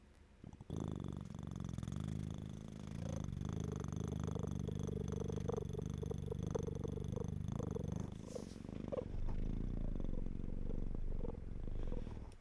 !attention fedi! i am a real cat i can even purr here's evidence yes you may pet me (1 attachments)